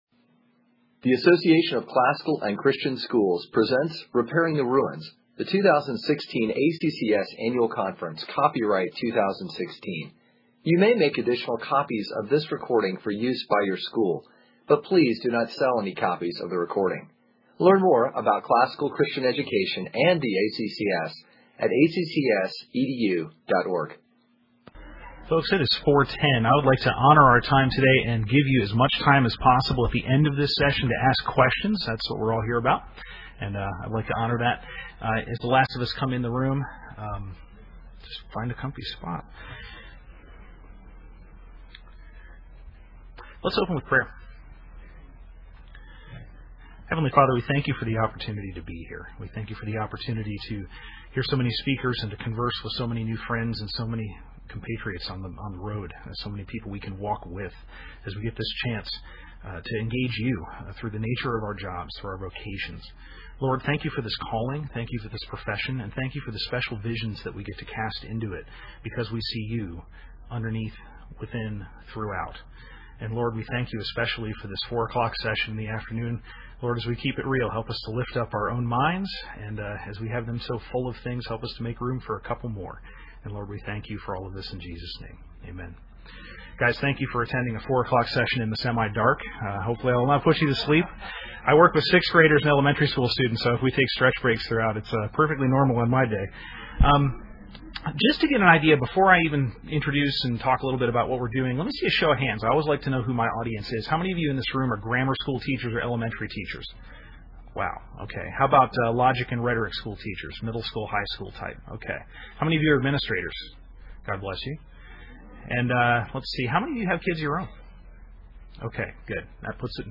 2016 Workshop Talk | 0:56:51 | K-6, Science